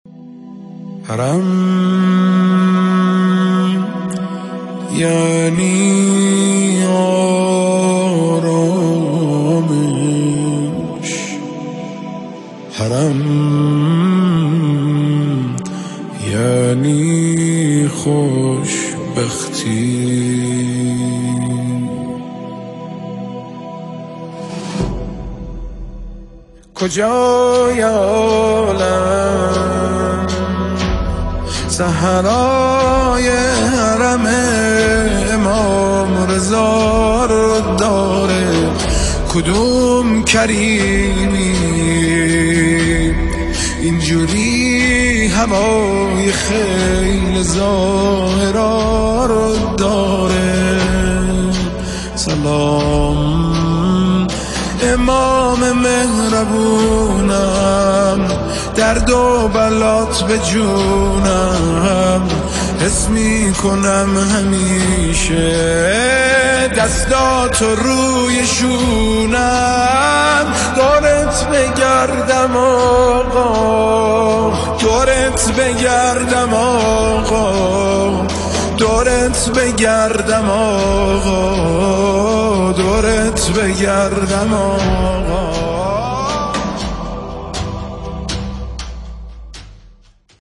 نماهنگ بسیار زیبا و شنیدنی